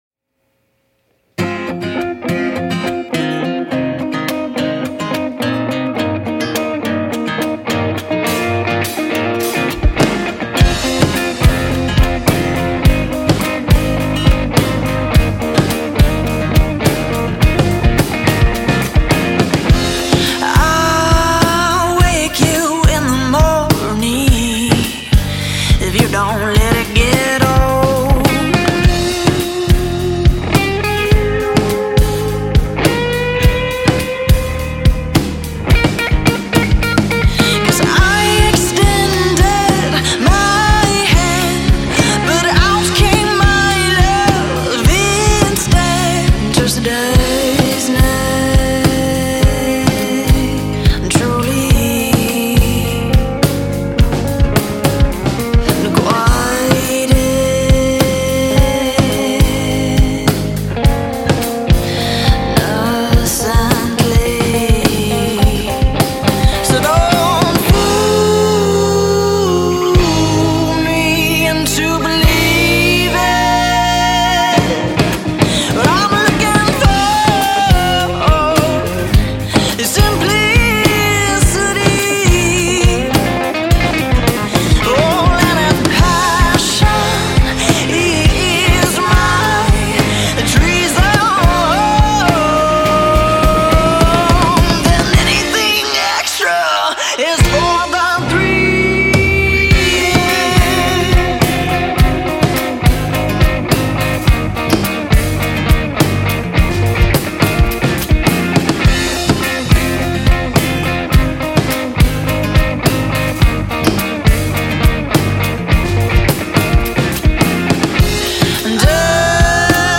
3-piece Indie Band